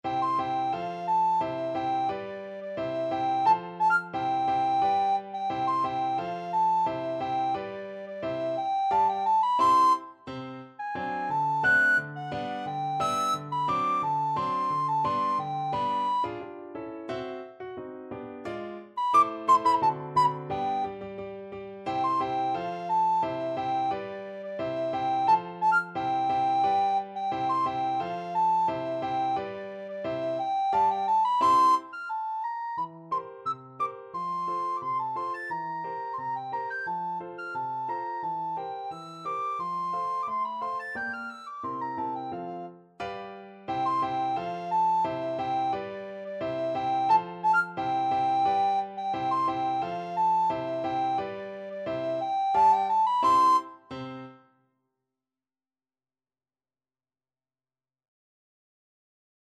Classical Strauss II,Johann Fledermaus-Quadrille, Op.363 Soprano (Descant) Recorder version
C major (Sounding Pitch) (View more C major Music for Recorder )
2/4 (View more 2/4 Music)
~ = 88 Stately =c.88
Classical (View more Classical Recorder Music)